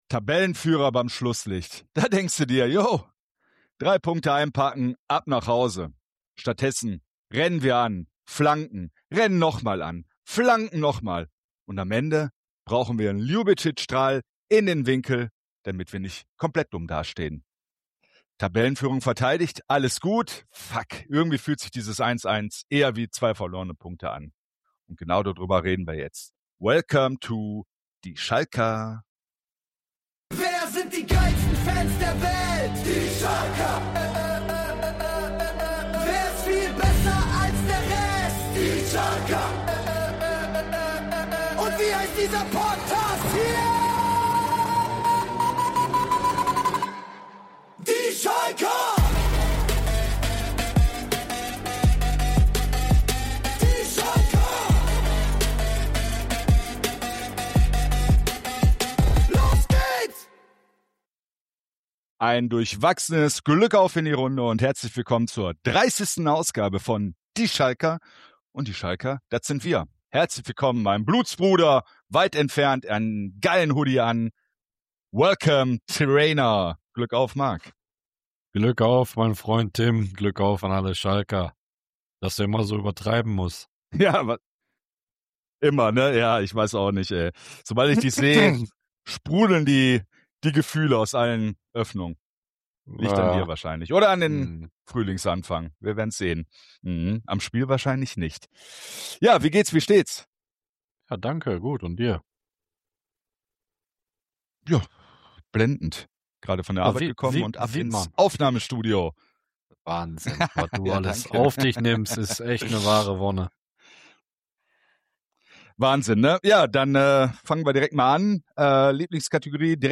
Dazu haben wir wieder Stimmen live vor Ort – ehrliche Reaktionen direkt nach Abpfiff, direkt aus dem Stadion, direkt aus der königsblauen Gefühlslage.